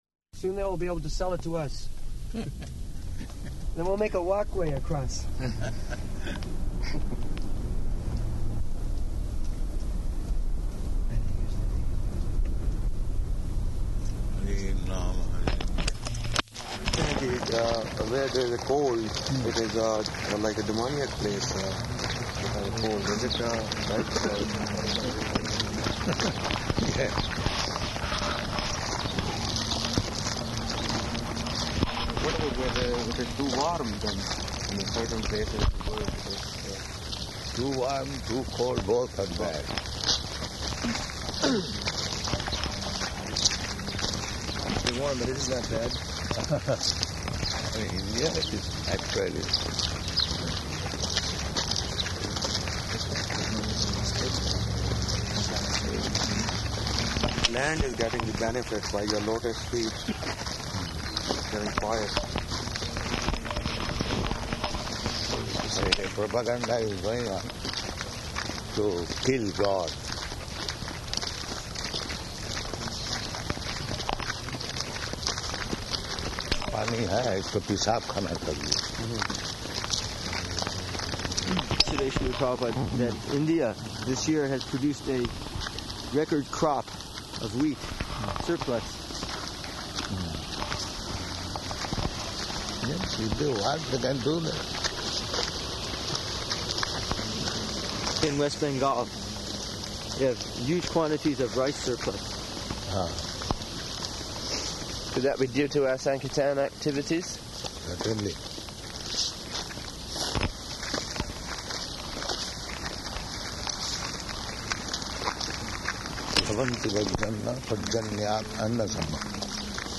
June 18th 1976 Location: Toronto Audio file